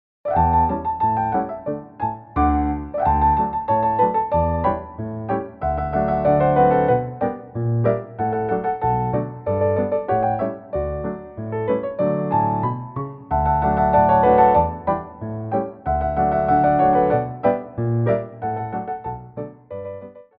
2/4 (8x8)